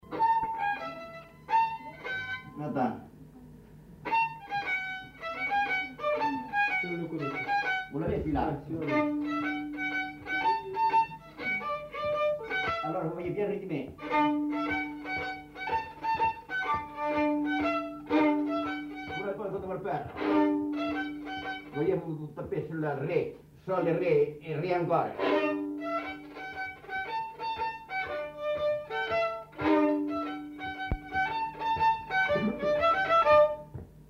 Aire culturelle : Astarac
Lieu : Orbessan
Genre : morceau instrumental
Instrument de musique : violon
Danse : rondeau